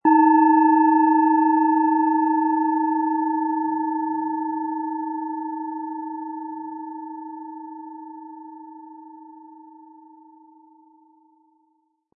Planetenton 1
Tibetische Planetenschale Venus von Hand gearbeitet.
Das Klangbeispiel spielt ihnen den Ton der hier angebotenen Schale ab.
MaterialBronze